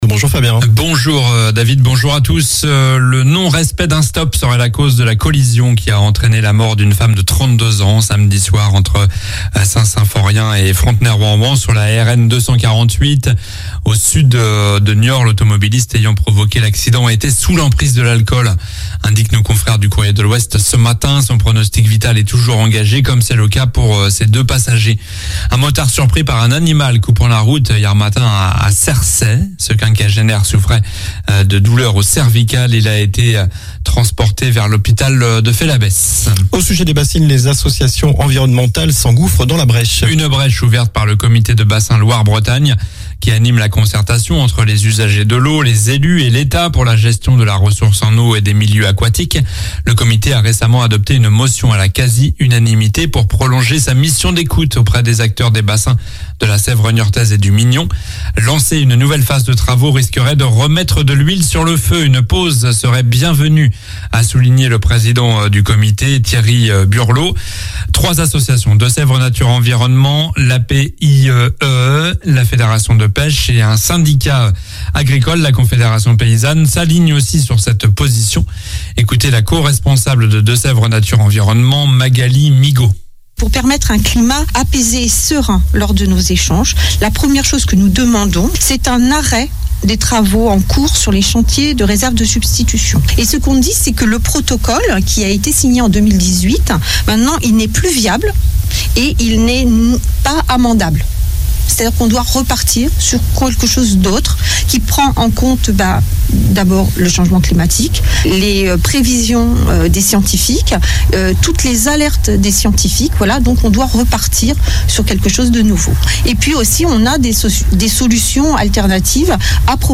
Journal du mardi 18 juillet (midi)